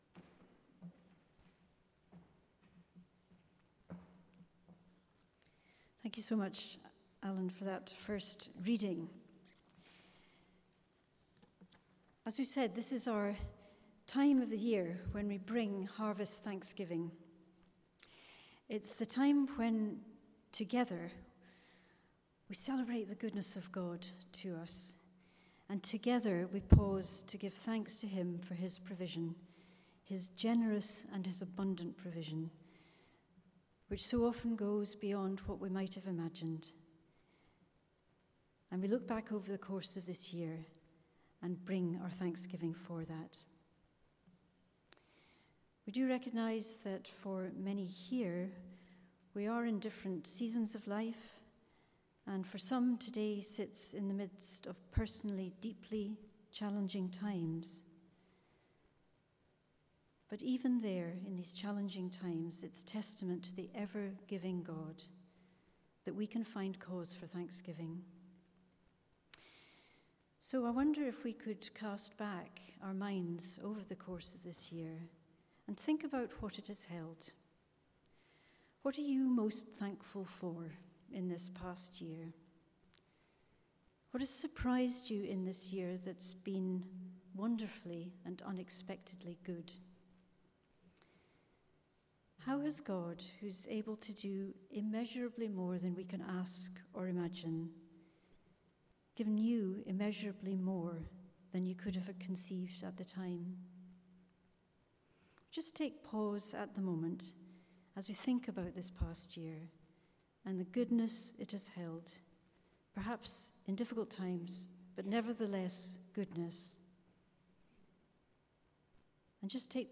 3-8 Service Type: Sunday Morning An Eco Harvest « Living for Christ Jesus